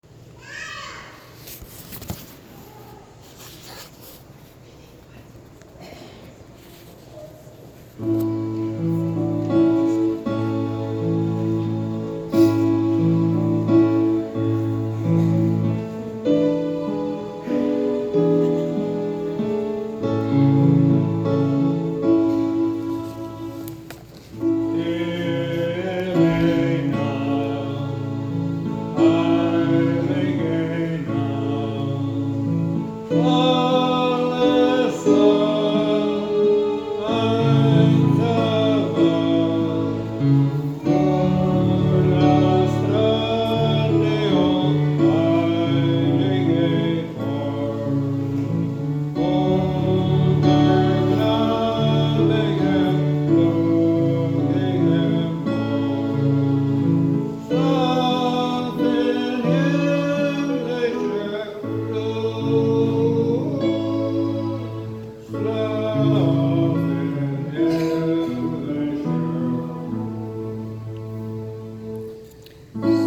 Here’s a link to my reflection on Stille Nacht, and be sure to scroll below for photos and original audio from St. Boniface Catholic Church in Monterey, Nebraska.
to sing on Christmas Eve